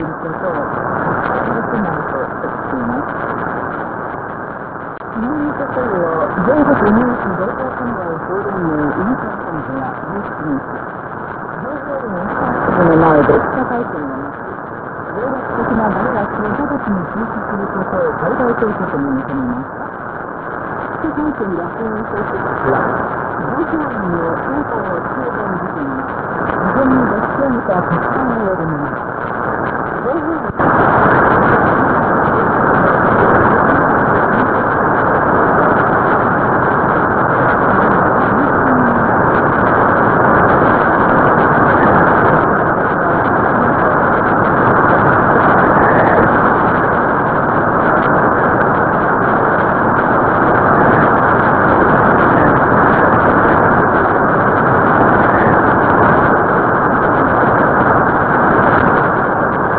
大阪市内にお住まいのDxerの方が、早速ΔLOOP10を製作され、21時頃3250kHzのKCBSの放送を、ΔLOOP10とALA-1530で比較してくださいました。
受信音：
これが受信音ですが、最初の30秒間がΔLOOP10で、後半30秒がALA1530です。
ものすごいノイズ除去能力ですね。
LOOP10vsALA.wav